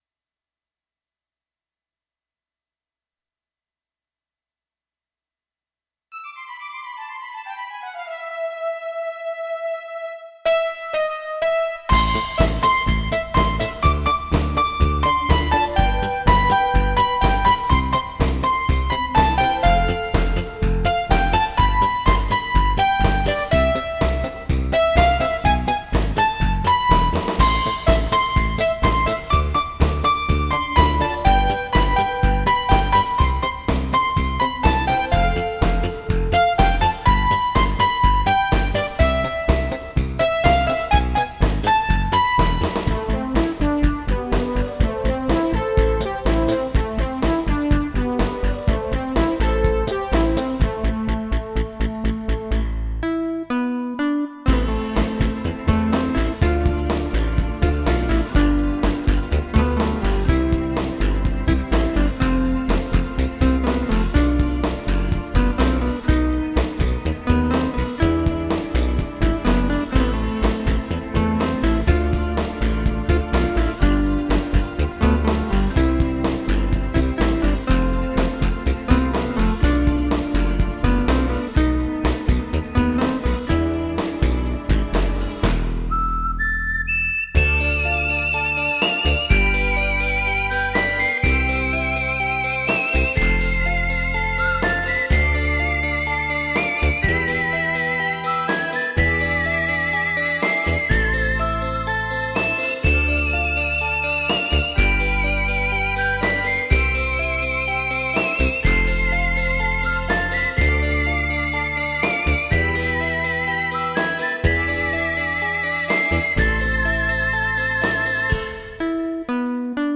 ちょっとスピード感を意識して作ったような気がします。